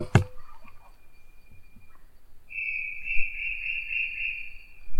Brown Tinamou (Crypturellus obsoletus)
Location or protected area: Parque Provincial Cruce Caballero
Condition: Wild
Certainty: Recorded vocal